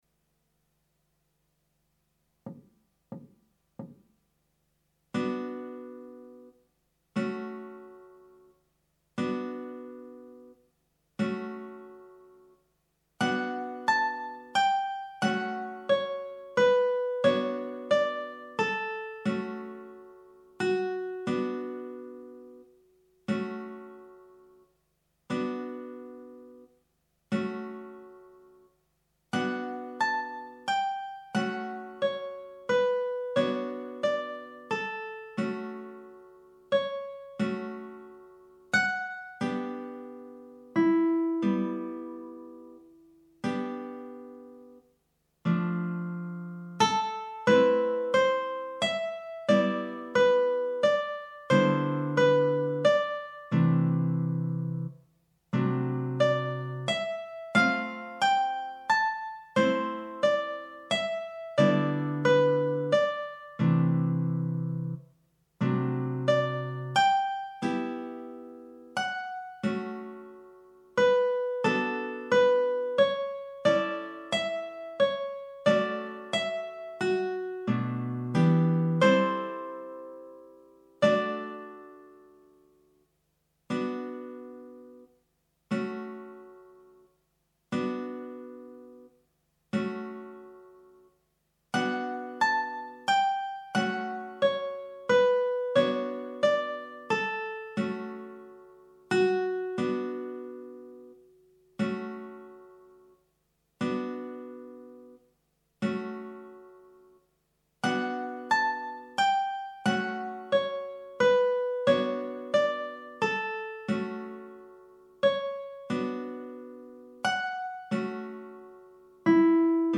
minus Guitar 1